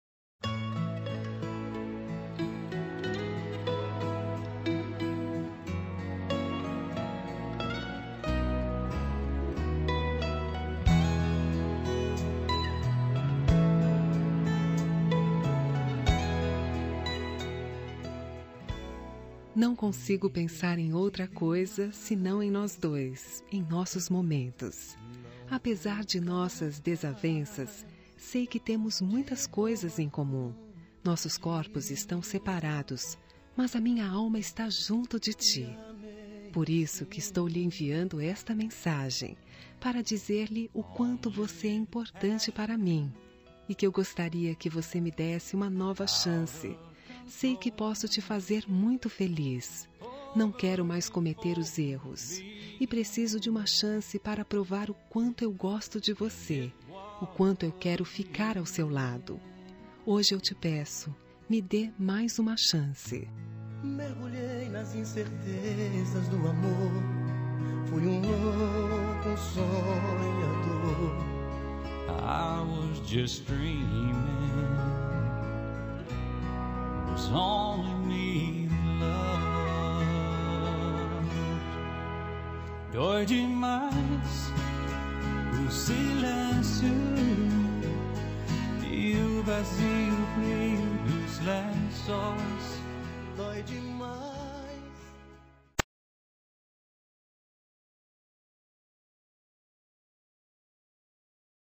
Telemensagem de Reconciliação – Voz Feminina – Cód: 7549